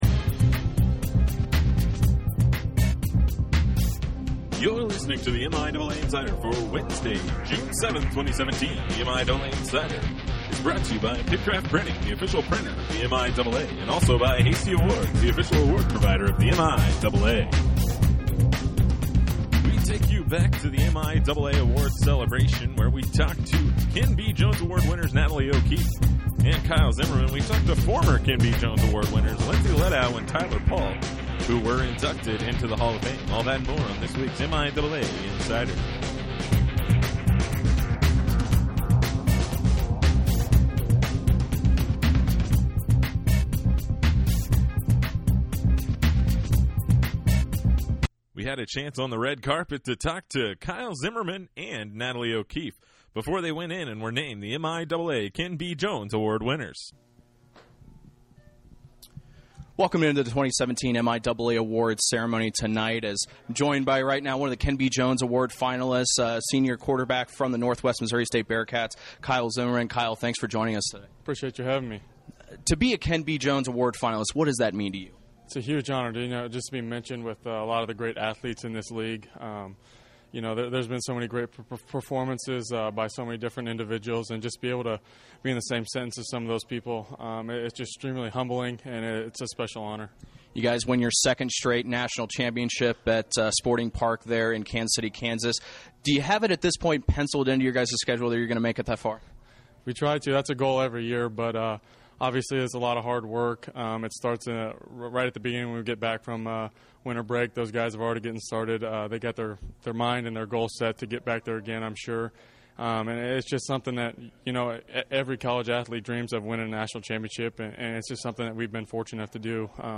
The Insider takes you back to the red carpet at the MIAA Awards Celebration for interviews